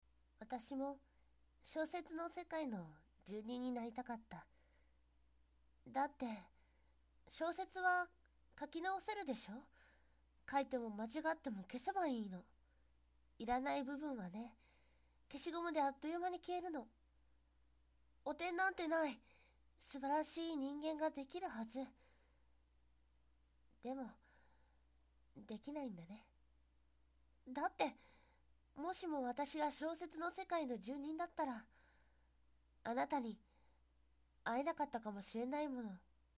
中音域の女性を得意としてやってます。 声域は広めです。 ロリとまではいきませんが少女や、少年数種、高めの青年声まで対応いたします。